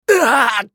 男性
厨二病ボイス～戦闘ボイス～
【ダメージ（強）2】